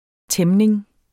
Udtale [ ˈtεmneŋ ]